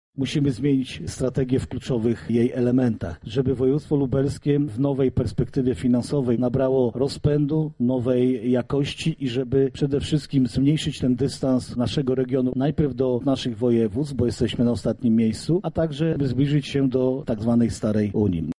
O tym jakie zmiany będą poddane konsultacjom mówi Marszałek Województwa Lubelskiego Jarosław Stawiarski: